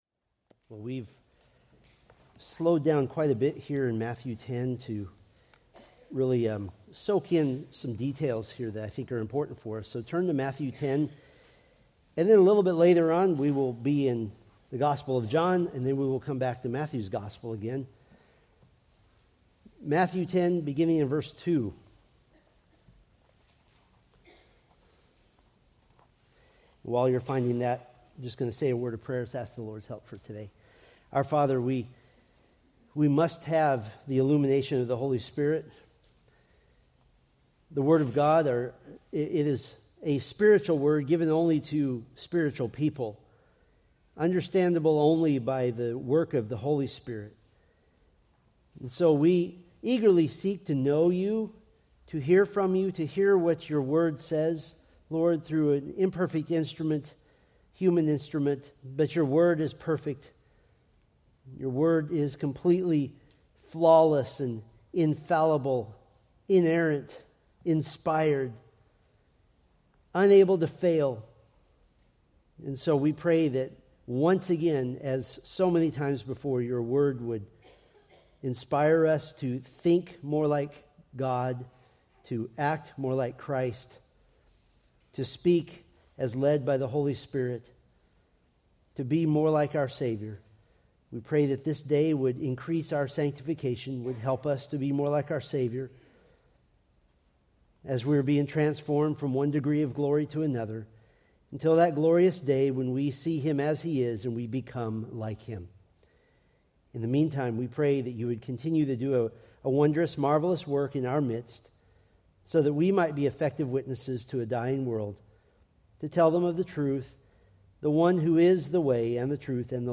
Preached March 2, 2025 from Matthew 10:3